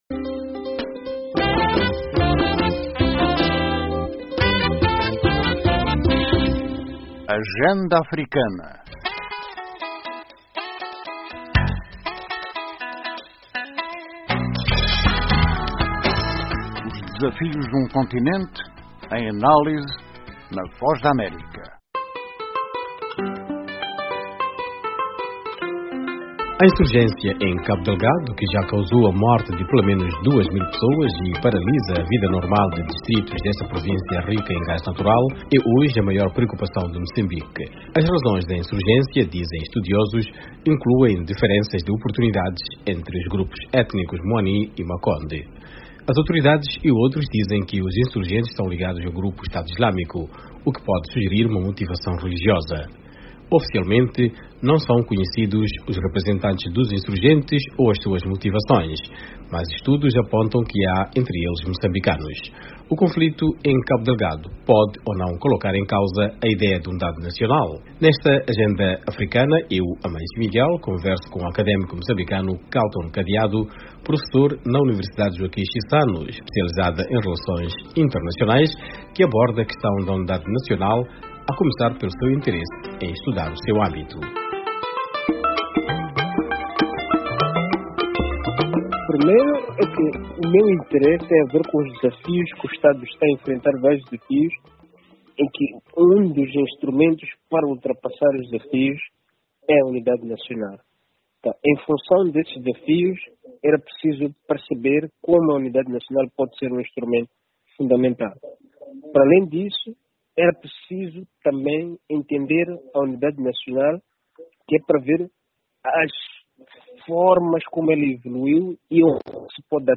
Ministro moçambicano da Defesa celebra conquistas em Cabo Delgado, mas analistas sugerem cautela Nesta entrevista, que abre a...